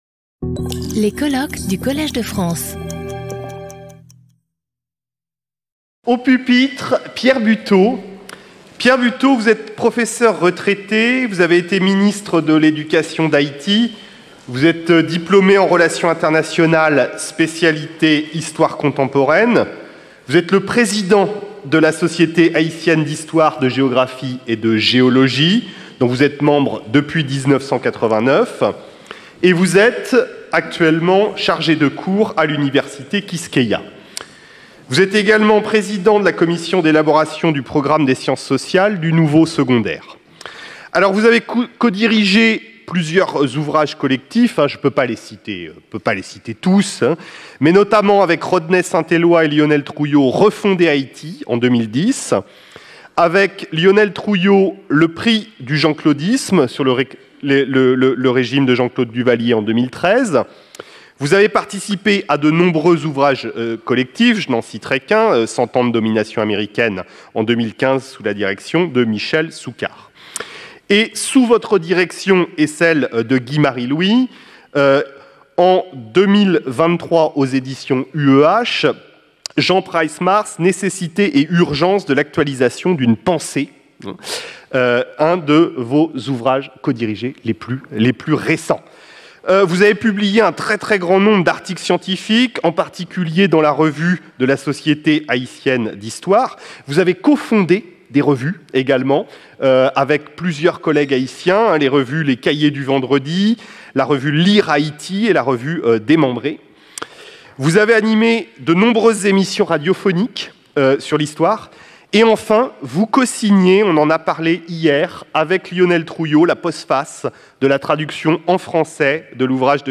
Symposium 13 Jun 2025 11:00 to 11:30